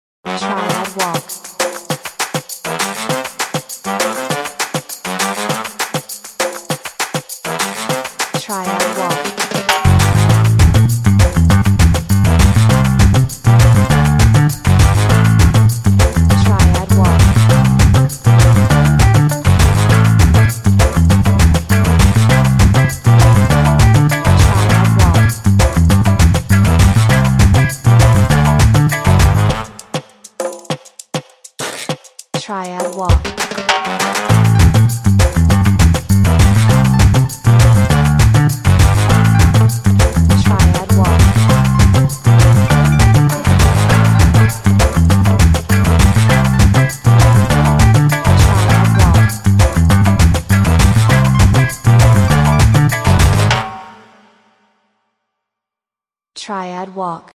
Up-beat with fast-moving brass and bass